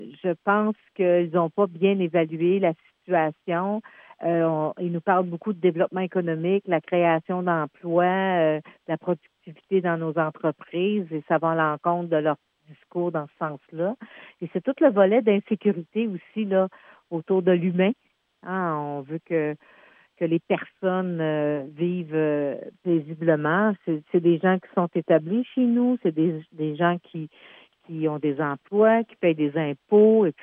En entrevue avec le Service de nouvelles de M105, lorsqu’on demande de s’adresser directement à la Coalition Avenir Québec (CAQ), voici ce que Madame Beauregard répond: